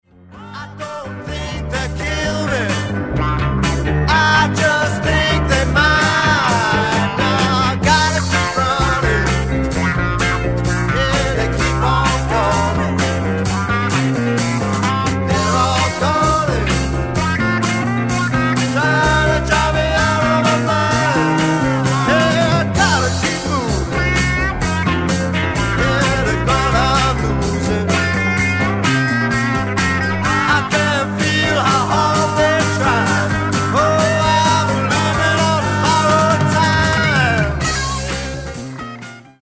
BLUES ROCK / COUNTRY BLUES / SSW